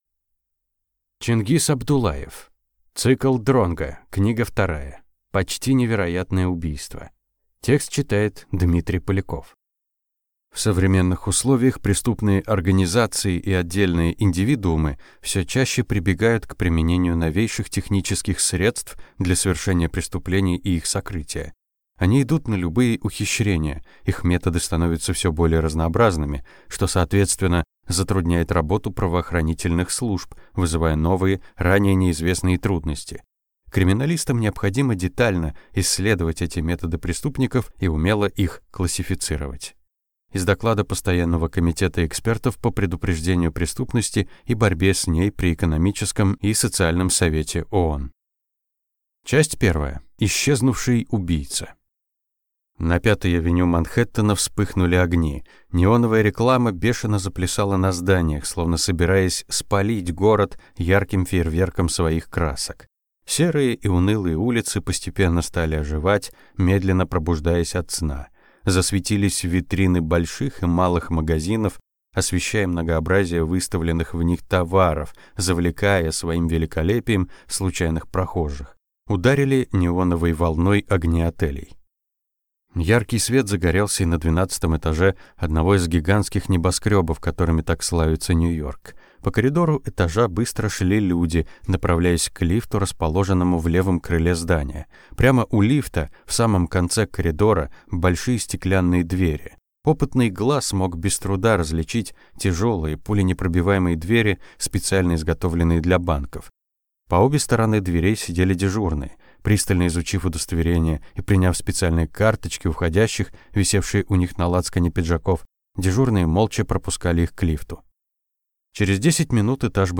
Аудиокнига Почти невероятное убийство | Библиотека аудиокниг